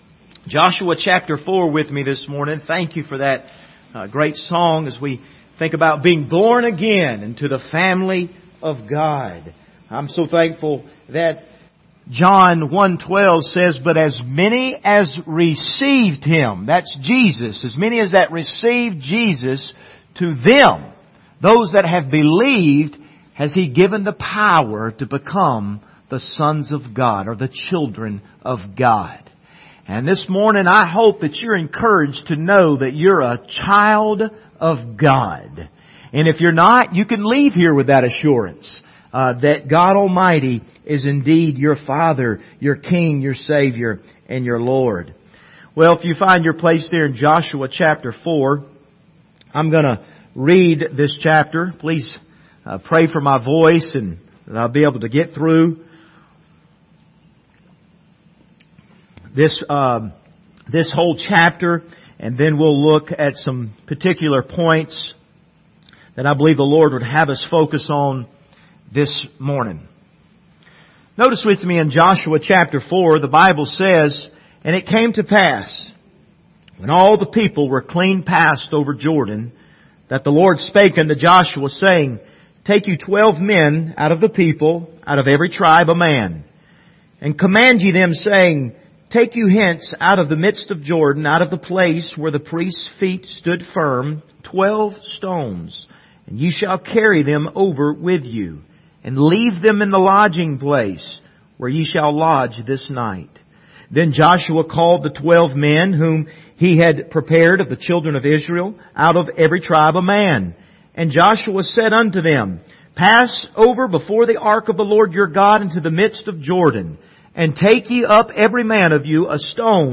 Passage: Joshua 4 Service Type: Sunday Morning